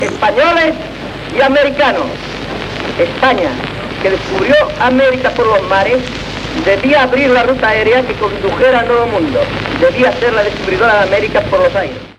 Paraules de l'aviador Ramon Franco després d'aterrar amb el "Plus Ultra" a Buenos Aires
Fragment extret de "Crònica Sentimental de Ràdio Barcelona", programa emès el dia 1 d'octubre de 1994